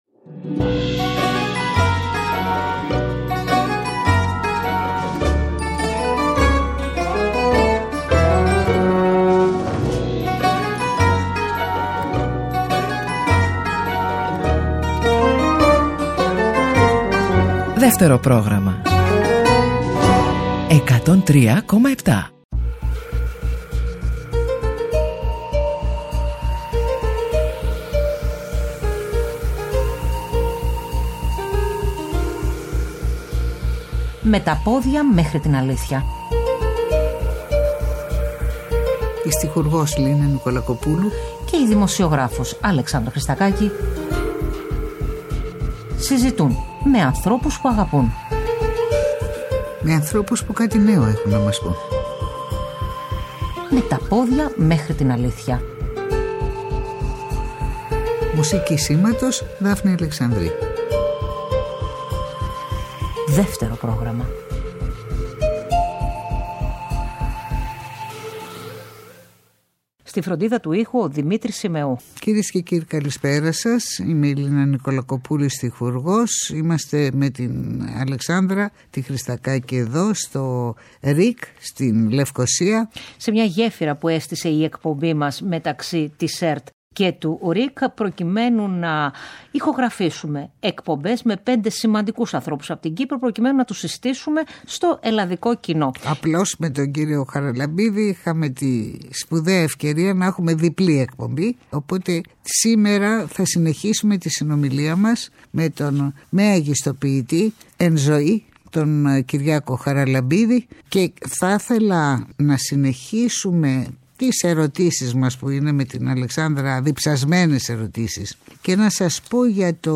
Συζητά στο στούντιο του ΡΙΚ με την στιχουργό Λίνα Νικολακοπούλου